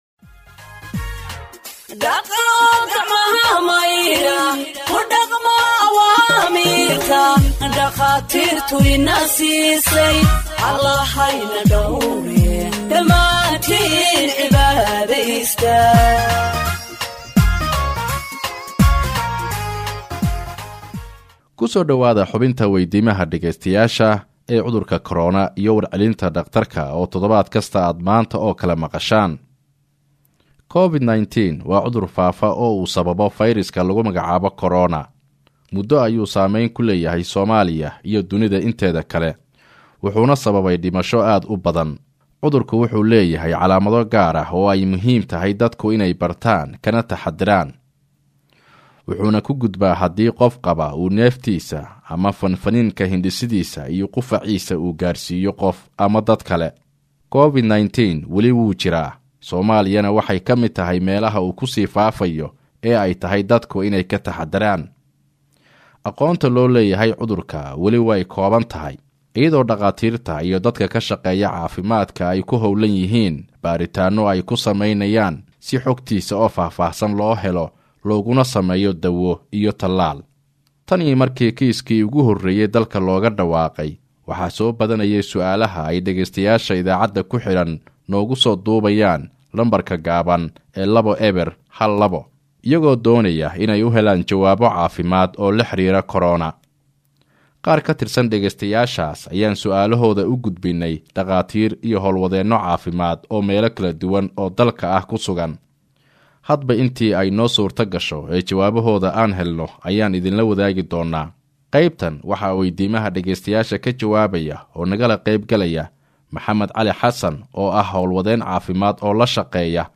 Health expert answers listeners’ questions on COVID 19 (38)
HEALTH-EXPERT-ANSWERS-LISTENERS-QUESTIONS-ON-COVID-19-38.mp3